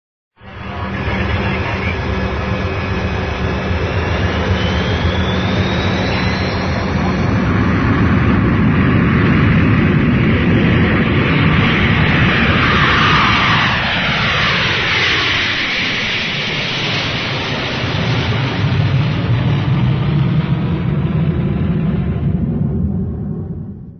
Song: Som de Avião Decolando - Efeito Sonoro_160k.mp3